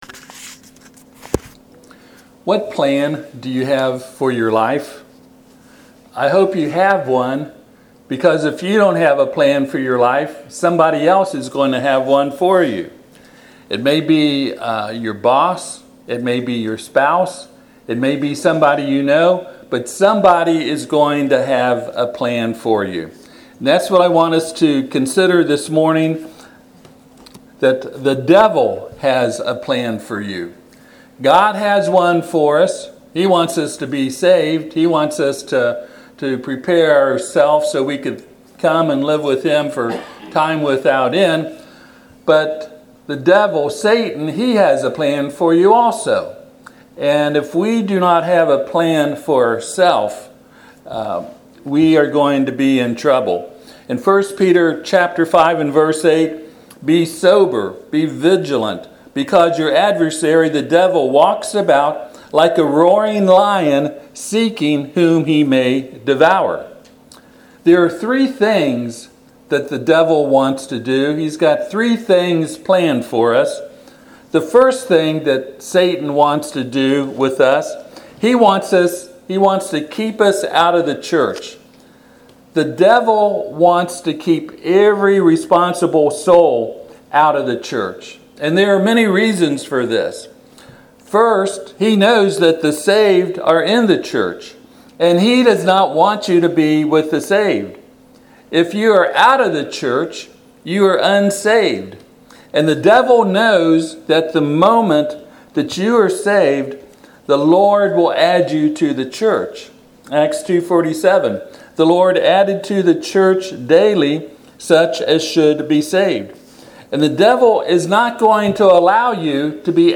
1 Peter 5:8 Service Type: Sunday AM « Precious Promises the Christian Has Finding The New Testament Church